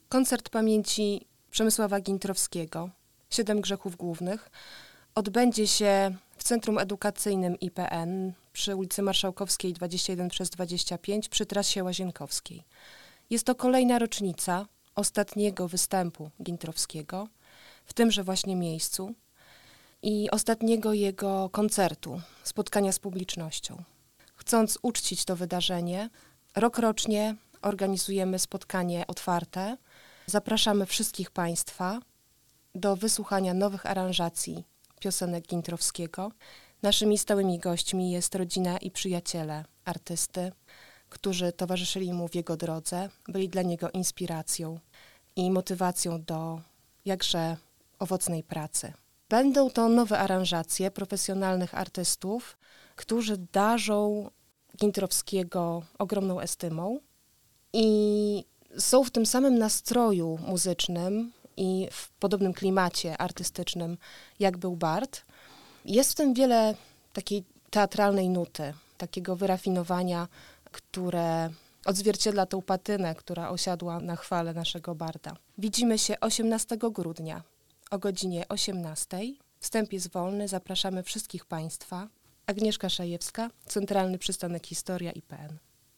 Sala koncertowa – poszerzona specjalnie na tę okazję o przestrzeń holu – wypełniła się do ostatniego miejsca.
Nowe aranżacje utworów spotkały się z bardzo ciepłym przyjęciem publiczności, która nagradzała artystów długimi brawami.
wokal, gitara
wokal, pianino
wokal, skrzypce